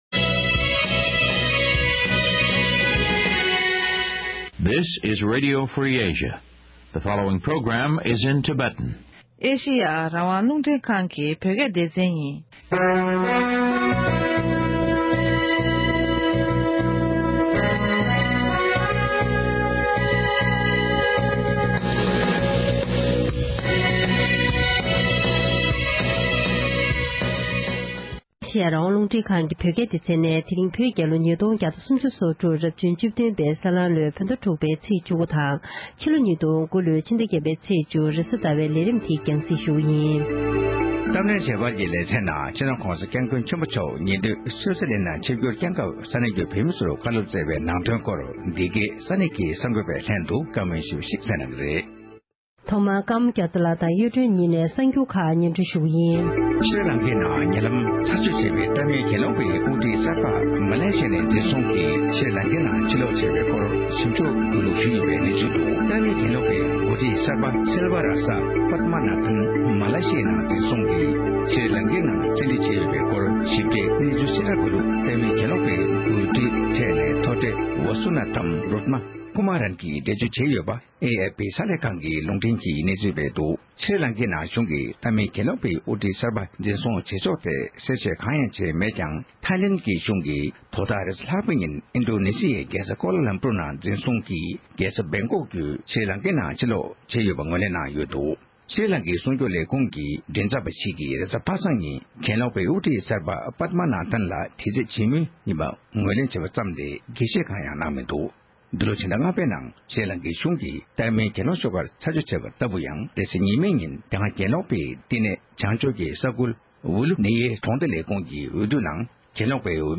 གླེང་མོལ་གནང་བར་མུ་མཐུད་གསན་རོགས༎